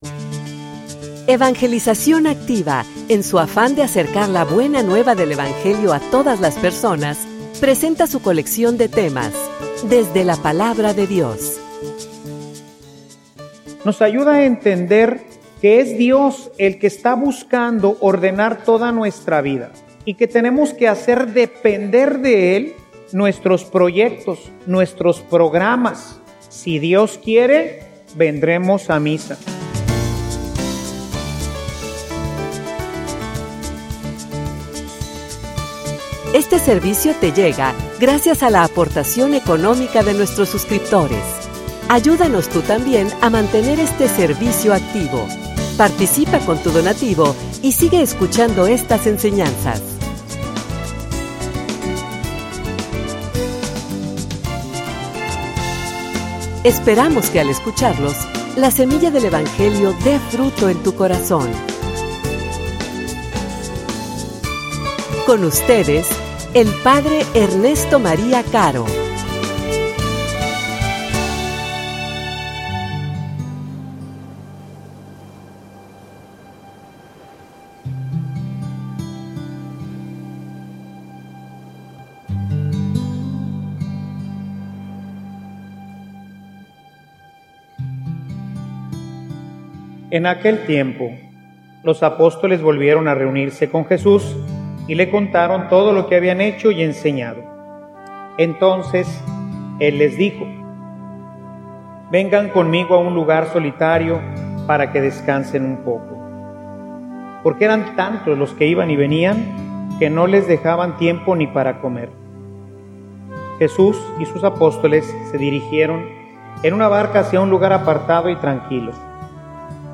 homilia_Docilidad_al_Espiritu.mp3